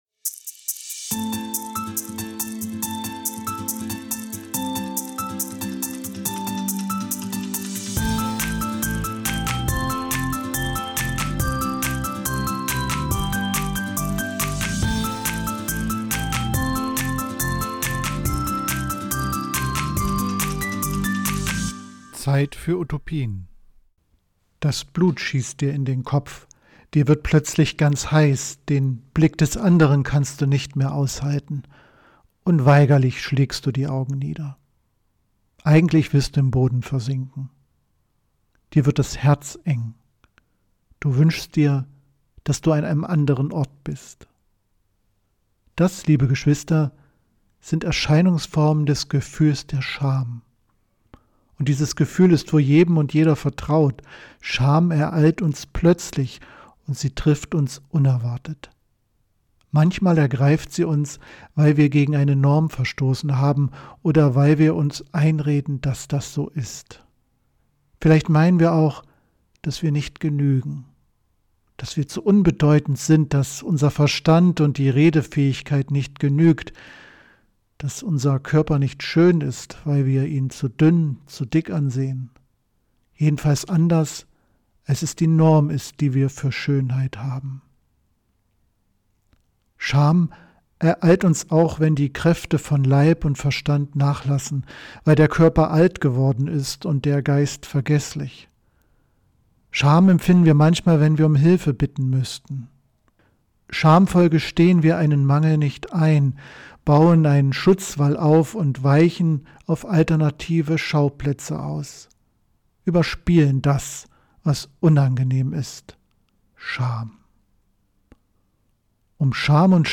Predigt zum Gründonnerstag am 17.4.2025 in der Kathedrale St. Sebastian, Magdeburg